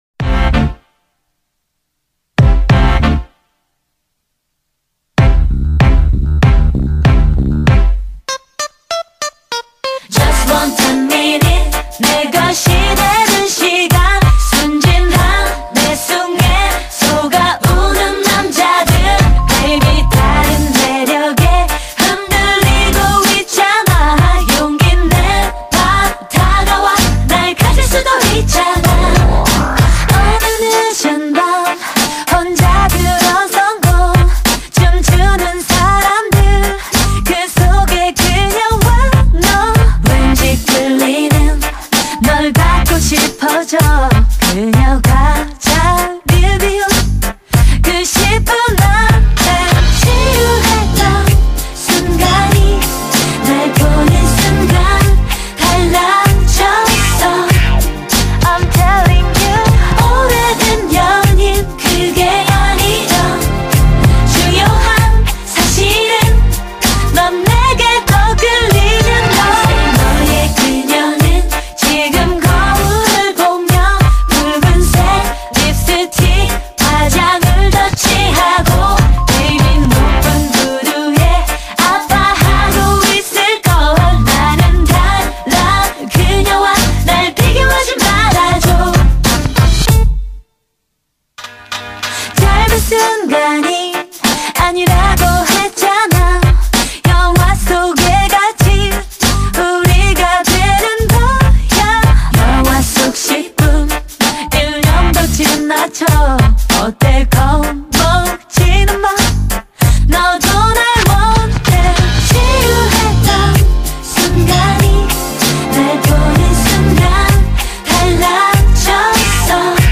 量身打造车载音响空间，全方位360度极致环绕3D音效